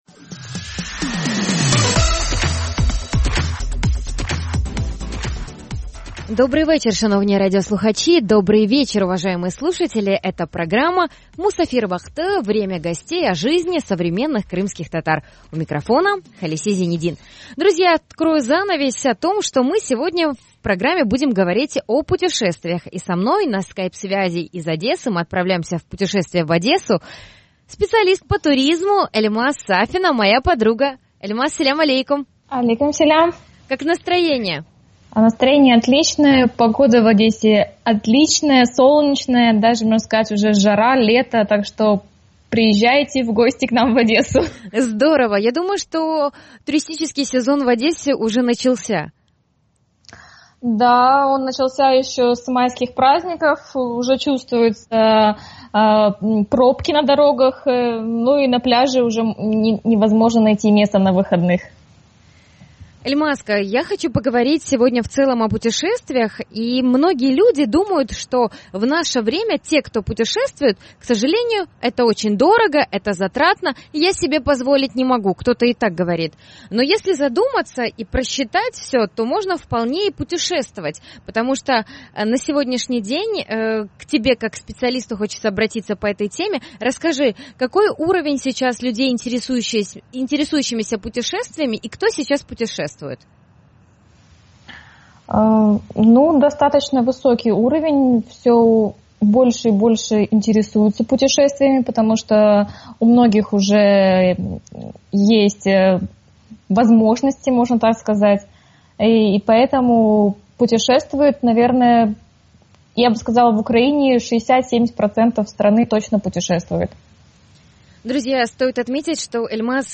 Наша гостья – специалист по туризму.